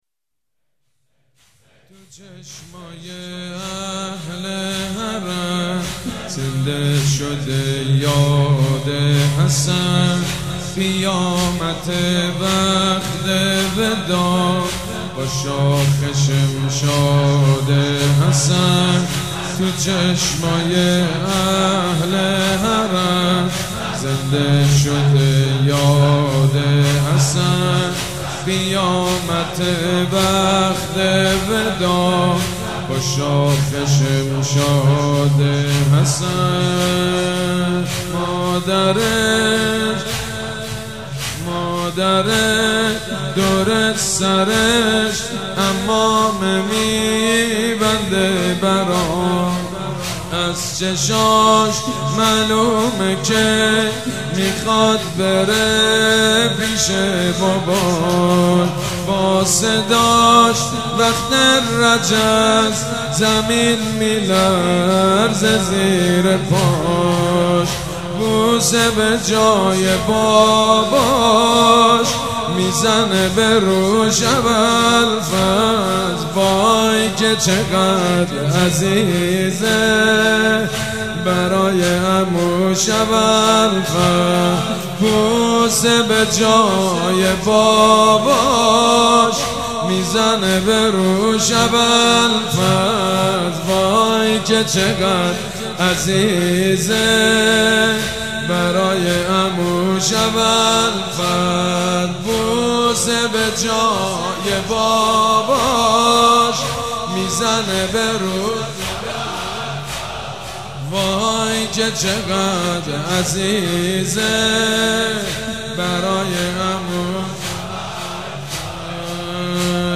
محرم 96 - زمینه - تو چشمای اهل حرم